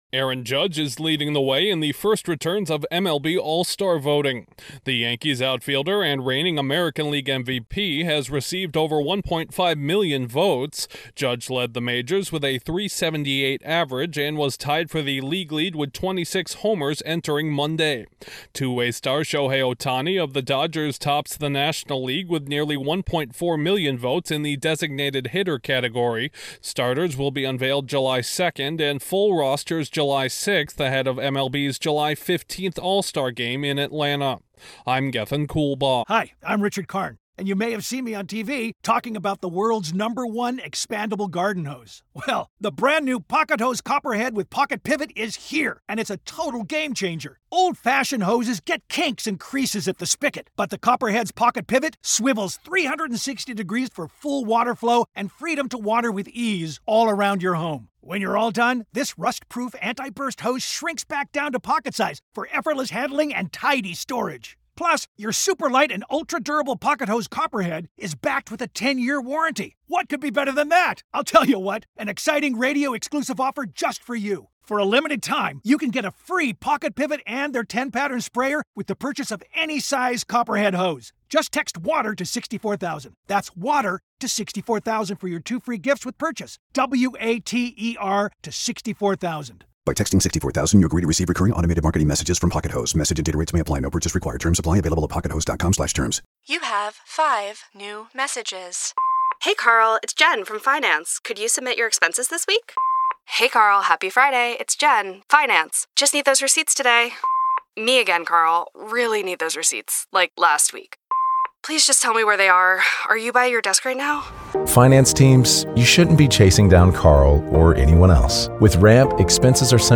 Baseball’s two biggest stars are leading the pack in All-Star voting. Correspondent